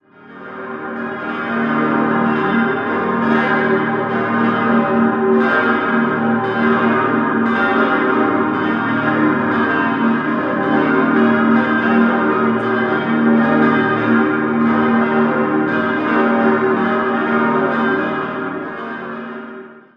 Idealsextett: g°-b°-c'-es'-f'-g' Die Glocken wurden im Jahr 1963 von der Gießerei Rüetschi in Aarau gegossen.